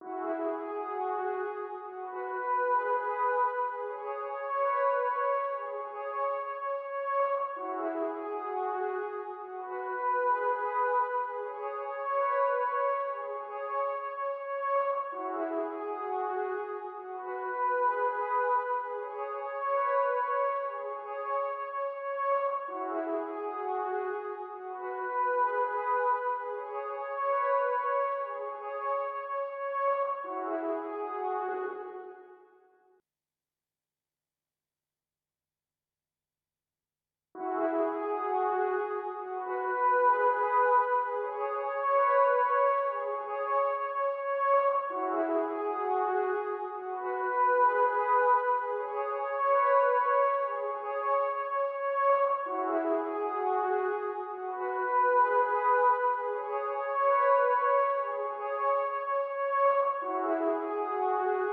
violons_studio_strings_rec01.wav